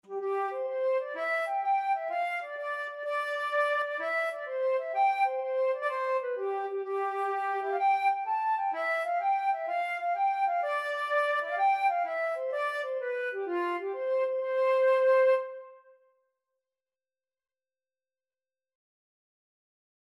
6/8 (View more 6/8 Music)
F5-A6
C major (Sounding Pitch) (View more C major Music for Flute )
Instrument:
Flute  (View more Easy Flute Music)
Traditional (View more Traditional Flute Music)